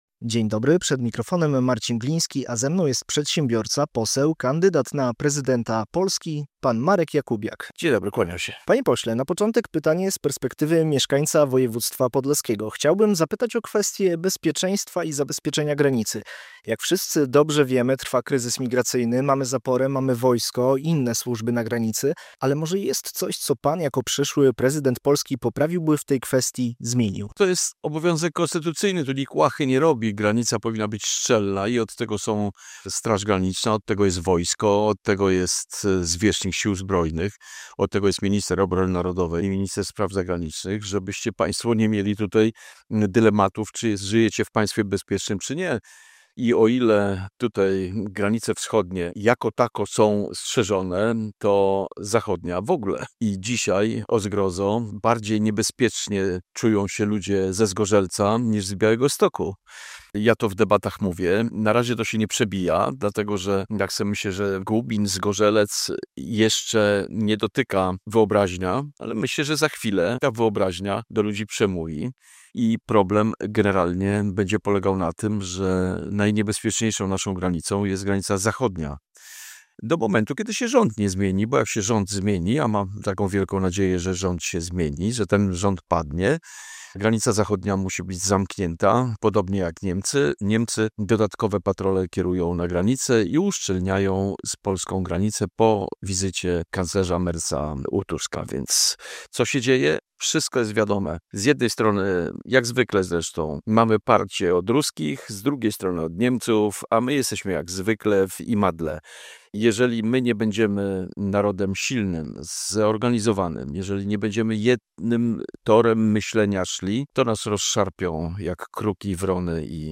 Radio Białystok | Gość | Marek Jakubiak - poseł, kandydat na prezydenta RP